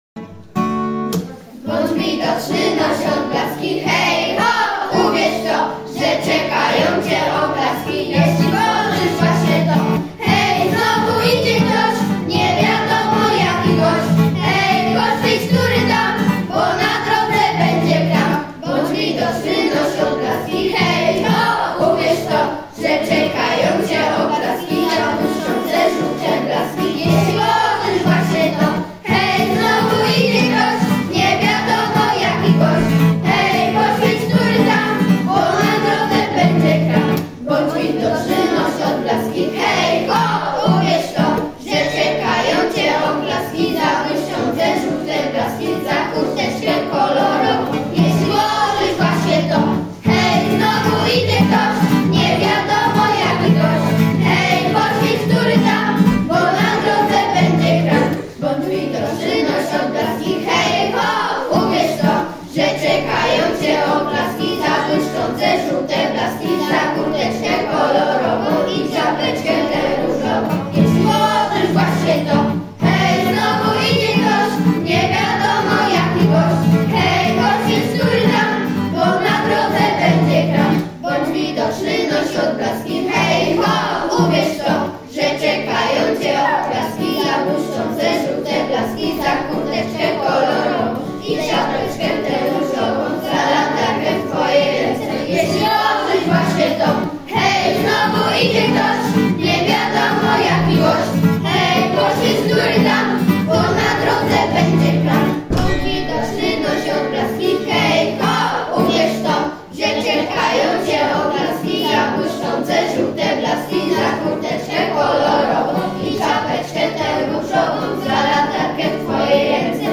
BĄDŹ WIDOCZNY, NOŚ ODBLASKI! – piosenka wykonana przez klasę V